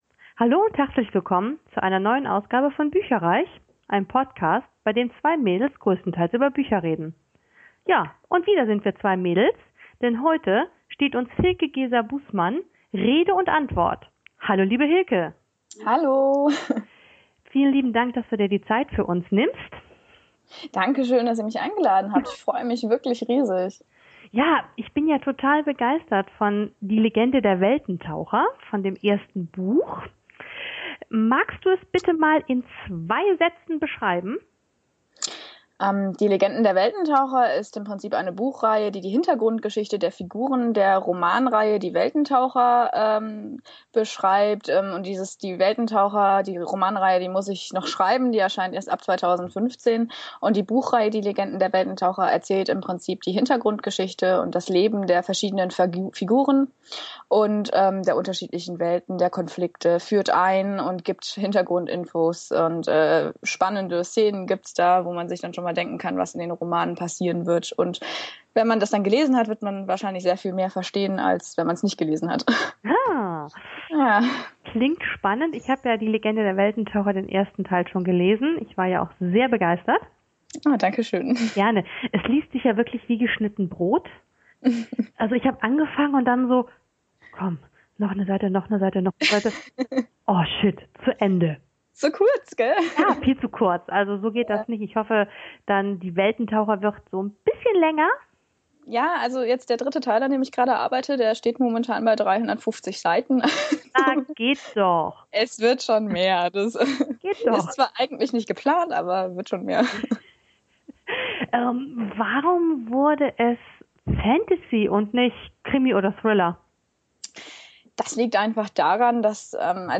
Beschreibung vor 11 Jahren Willkommen bei bücherreich, unserem Bücher-Podcast!
Entschuldigt bitte, dass die Tonqualität leider nicht auf unserem üblichen Niveau ist, da das Interview via Skype geführt und aufgenommen wurde.